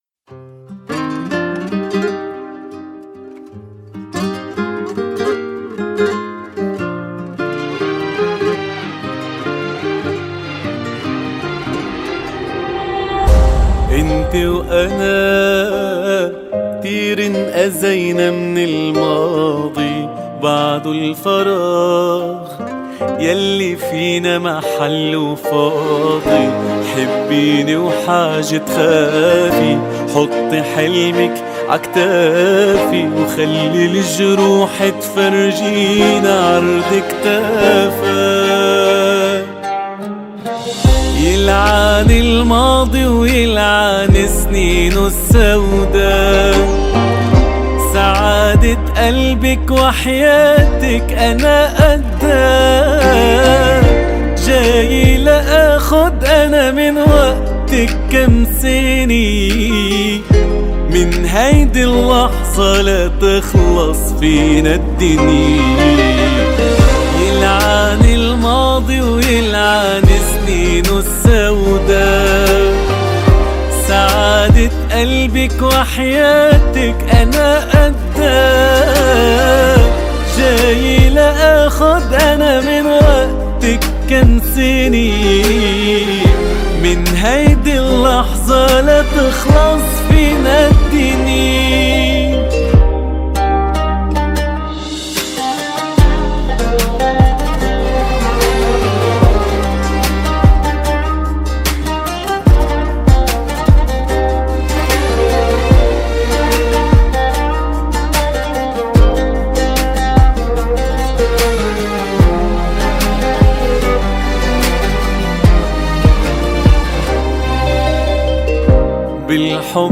دانلود آهنگ عربی